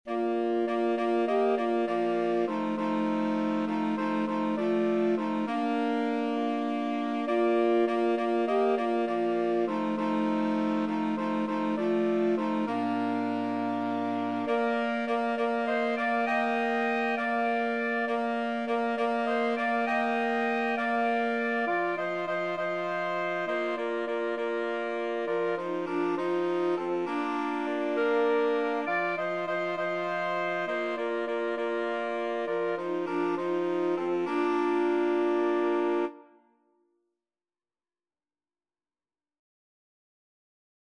6/8 (View more 6/8 Music)
Classical (View more Classical Saxophone Quartet Music)